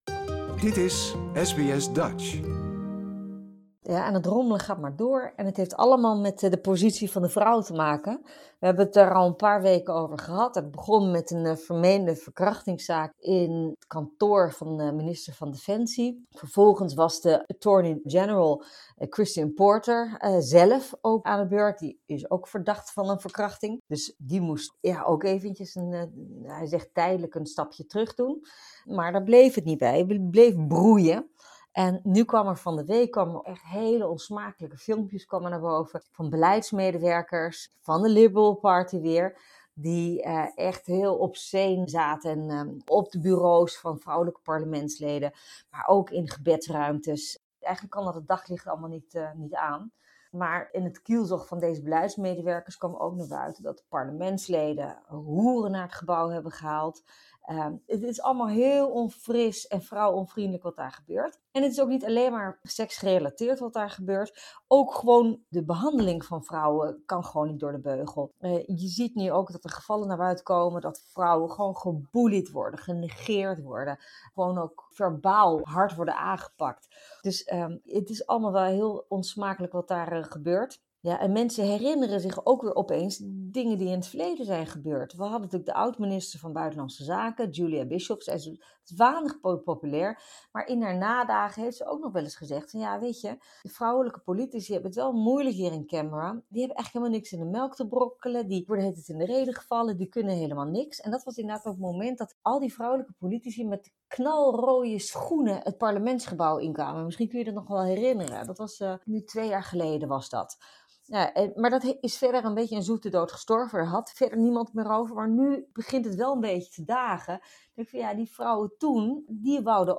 Meer een meer verhalen over vrouwonvriendelijk- en seksueel ongepast gedrag komen naar buiten. En in Nederland begint de formatie weer van voren af aan, nadat de Kajsa Ollongren van D66 per ongeluk met duidelijk leesbare notities langs fotografen lopen. Dat en meer bespreken we met politiek commentator Nicolien van Vroonhoven.